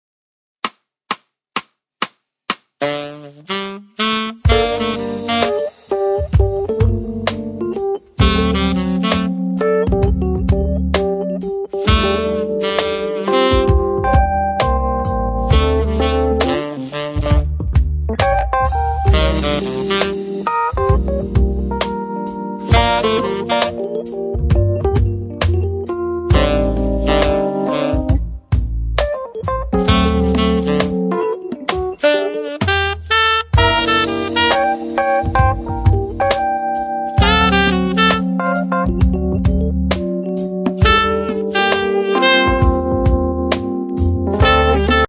Tenor/Soprano Saxophone Version